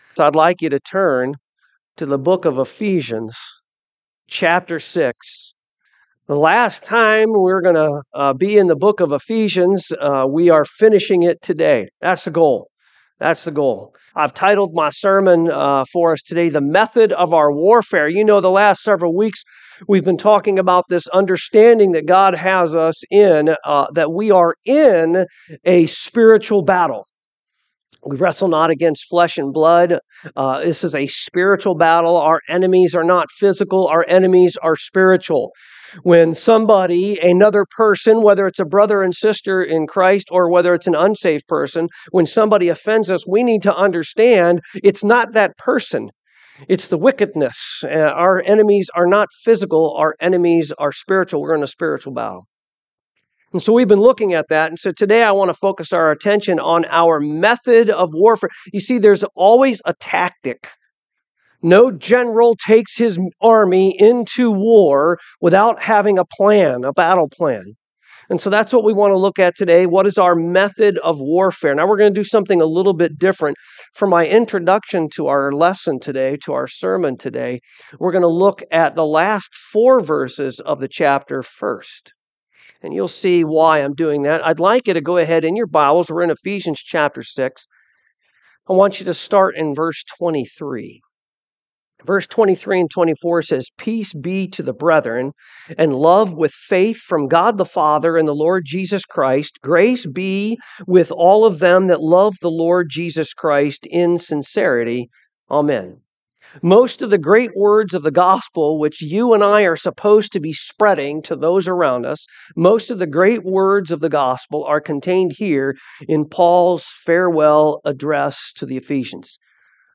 Ephesians 6:17-24 Service Type: AM Any soldier must have a weapon.